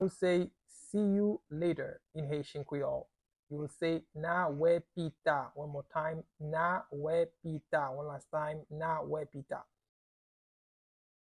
Pronunciation:
Listen to and watch “N a wè pita” audio pronunciation in Haitian Creole by a native Haitian  in the video below:
See-you-later-in-Haitian-Creole.mp3